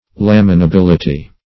Laminability \Lam`i*na*bil"i*ty\, n. The quality or state of being laminable.